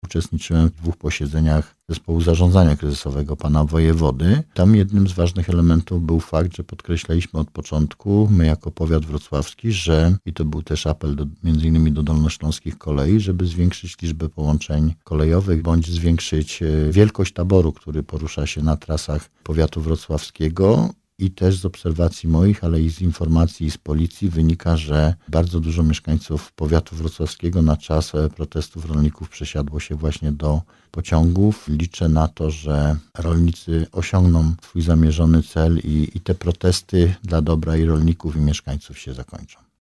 Mieszkańcy gmin należących do powiatu wrocławskiego, korzystają w ostatnich dniach z dojazdu do pracy lub szkoły pociągiem. – dodaje starosta.